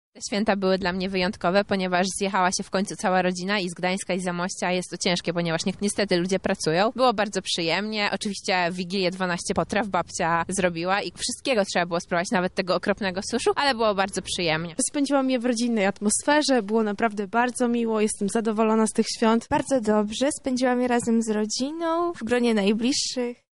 12 potraw, wolne miejsce dla nieoczekiwanego gościa. Mieszkańcy Lublina wspominają Święta Bożego Narodzenia
Zapytaliśmy mieszkańców Lublina czy dla nich ten czas był udany.